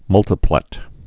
(mŭltə-plĕt, -plĭt)